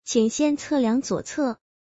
alert_left.mp3